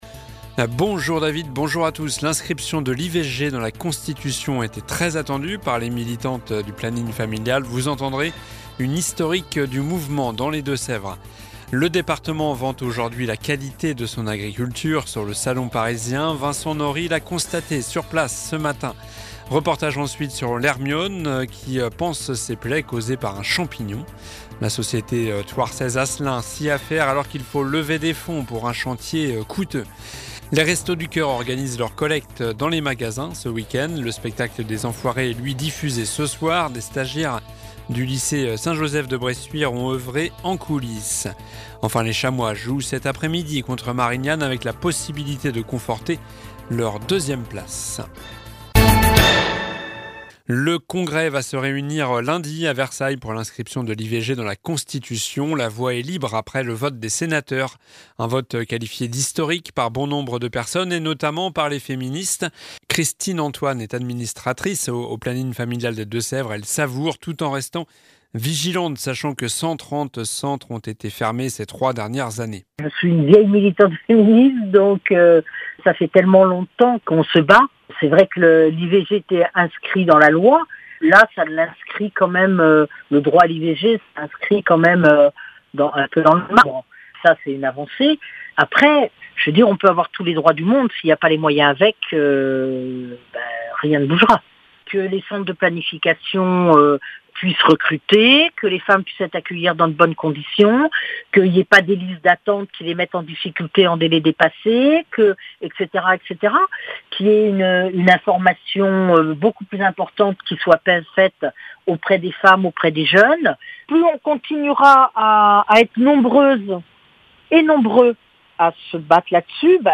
Journal du vendredi 1er mars (midi)